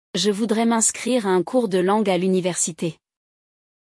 No episódio de hoje, você escutará uma interação real entre uma estudante e um funcionário da universidade, absorvendo a entonação, o ritmo e a estrutura natural do francês.